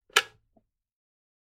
Звук: подножку электросамоката открыли, чтобы он не упал